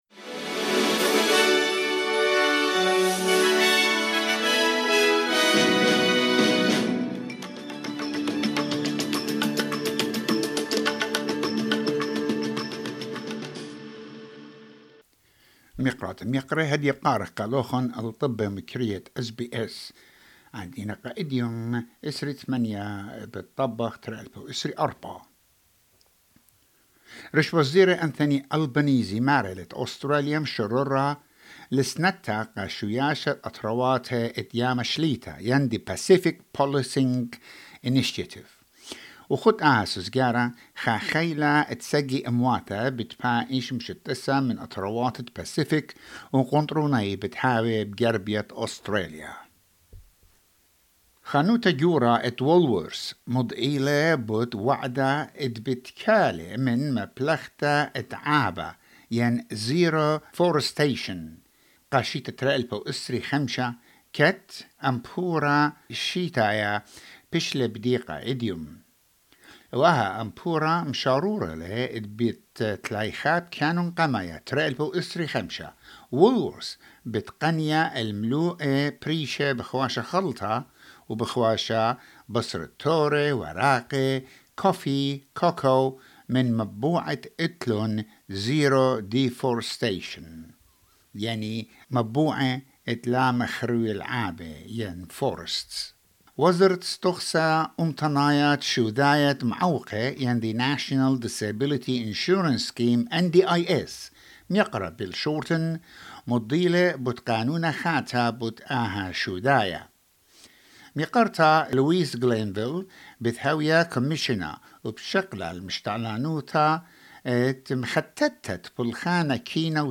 SBS Assyrian news bulletin: 28 August 2024